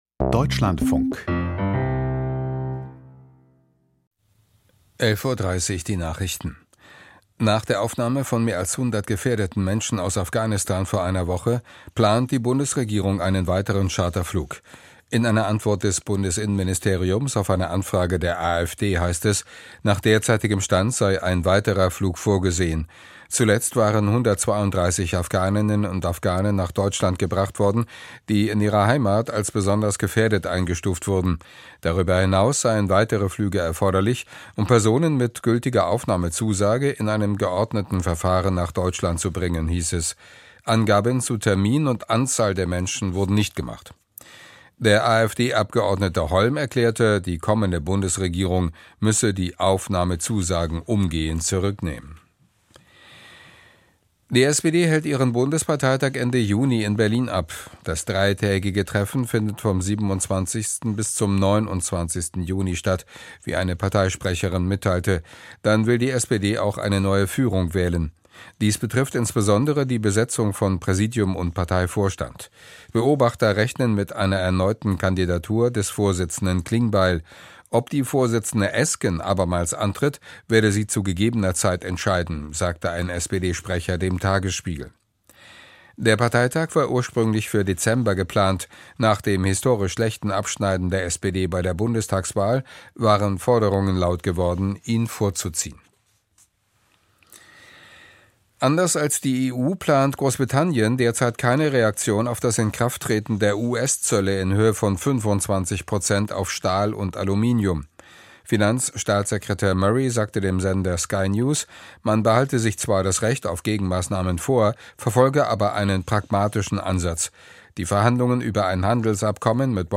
Die Deutschlandfunk-Nachrichten vom 12.03.2025, 11:30 Uhr